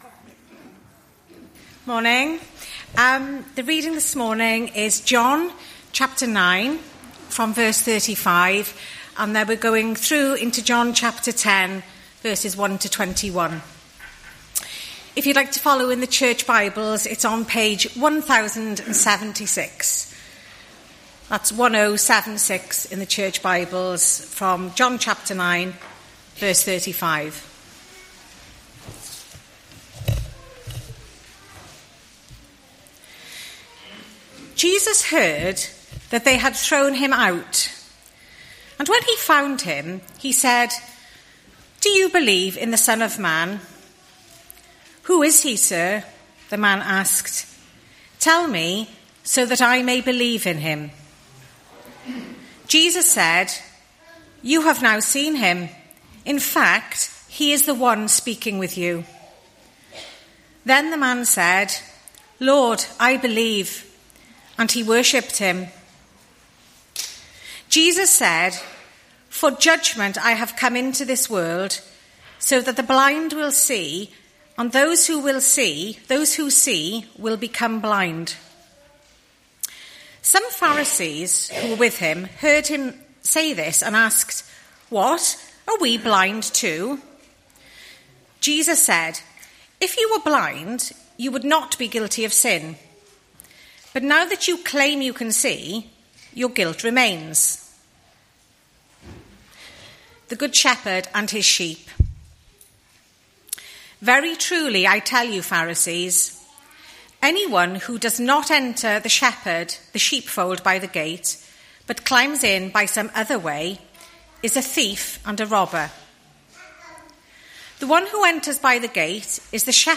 Morning Service.